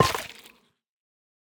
Minecraft Version Minecraft Version 25w18a Latest Release | Latest Snapshot 25w18a / assets / minecraft / sounds / block / sculk_catalyst / break6.ogg Compare With Compare With Latest Release | Latest Snapshot
break6.ogg